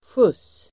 Uttal: [sjus: el. sjut:s]